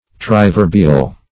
Search Result for " triverbial" : The Collaborative International Dictionary of English v.0.48: Triverbial \Tri*ver"bi*al\, a. [Pref. tri- + L. verbum a word.]
triverbial.mp3